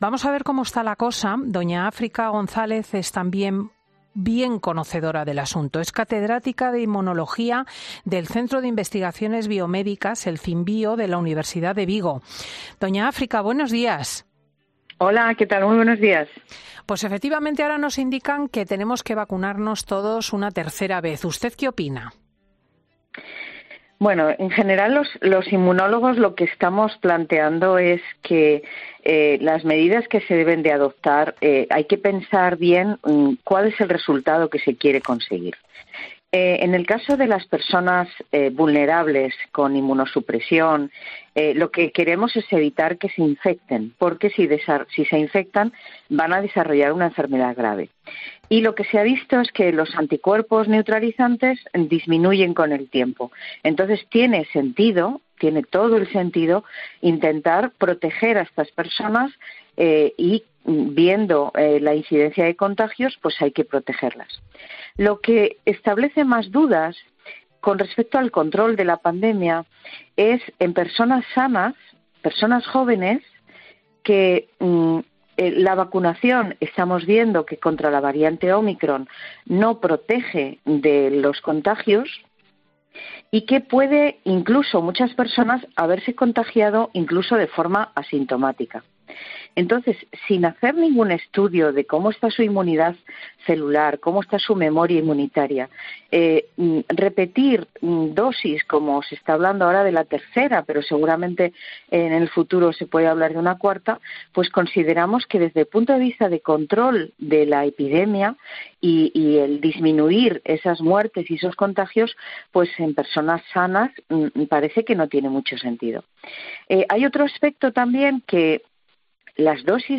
Lo hacía en los micrófonos de Fin de Semana en los que también ha querido aclarar cuál es el tiempo exacto que habría que esperar para administrar las diferentes dosis de vacunación.